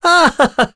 Roman-Vox_Happy1.wav